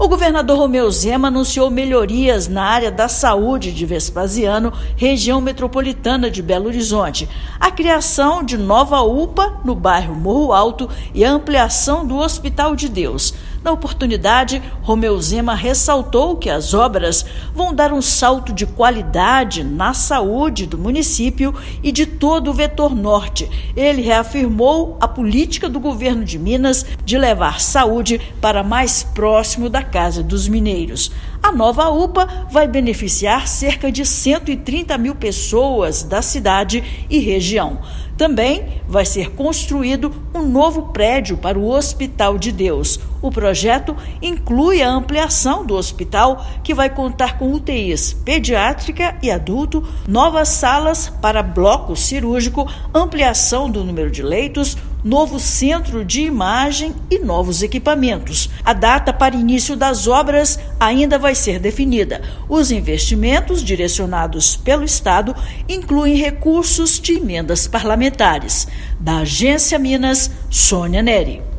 No Morro Alto, será criado uma nova UPA. Já o Hospital de Deus receberá novo prédio, com mais leitos, salas de cirurgia, centro de imagem e equipamentos. Ouça matéria de rádio.